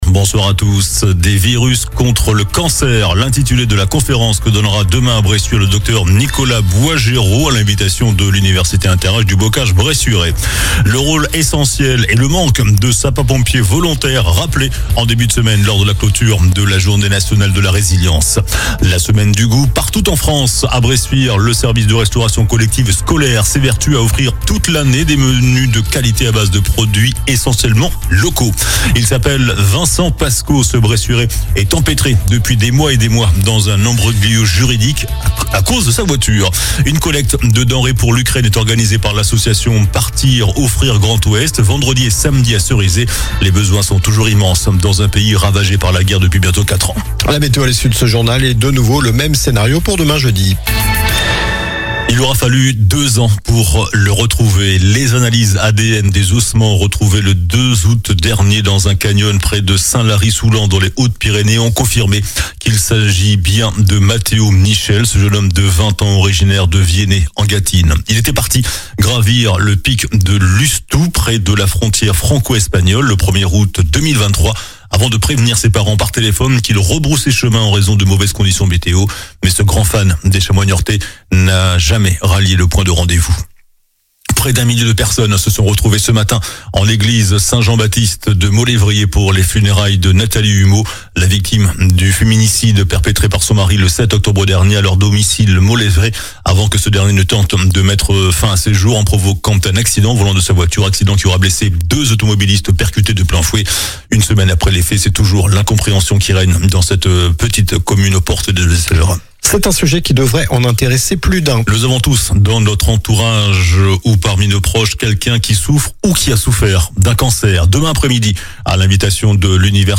JOURNAL DU MERCREDI 15 OCTOBRE ( SOIR )